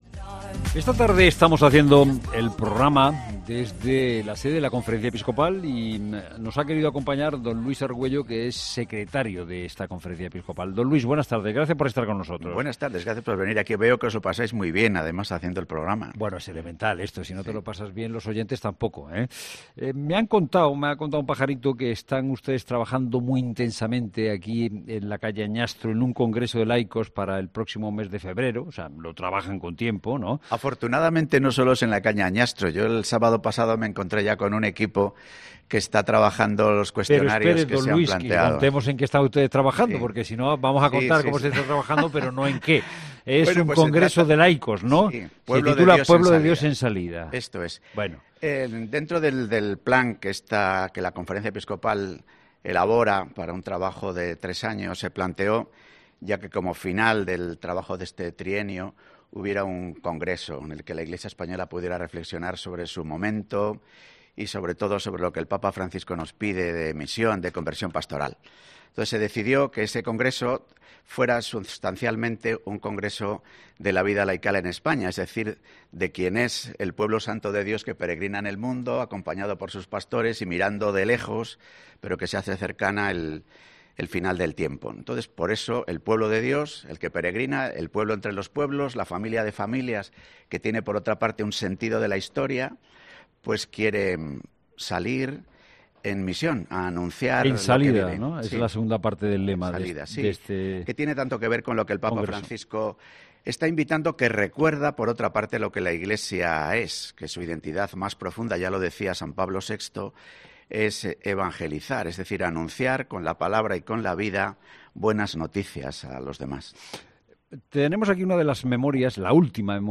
Entrevista a mons. Luis Argüello, secretario general de la CEE, en La Tarde de COPE